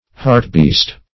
hartbeest - definition of hartbeest - synonyms, pronunciation, spelling from Free Dictionary
Hartbeest \Hart"beest`\, Hartebeest \Har"te*beest`\(-b[=e]st`),